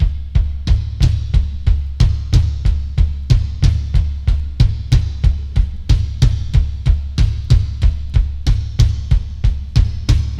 • 92 Bpm Drum Loop D Key.wav
Free drum loop - kick tuned to the D note. Loudest frequency: 280Hz
92-bpm-drum-loop-d-key-gru.wav